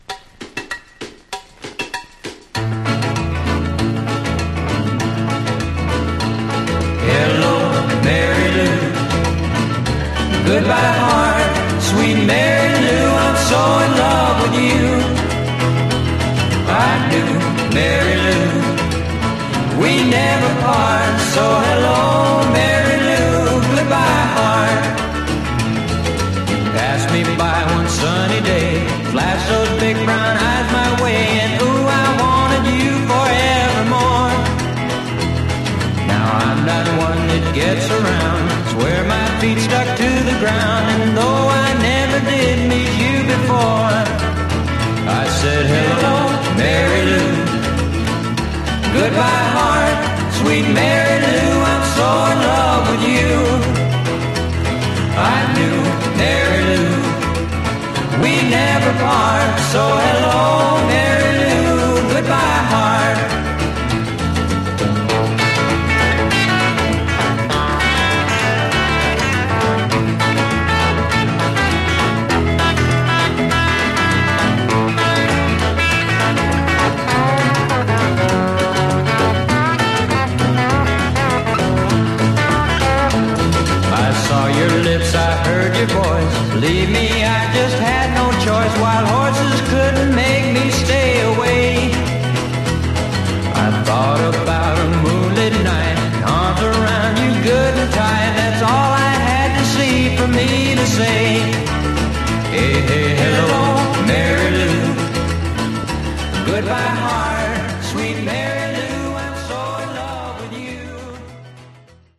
Genre: Rock 'n' Roll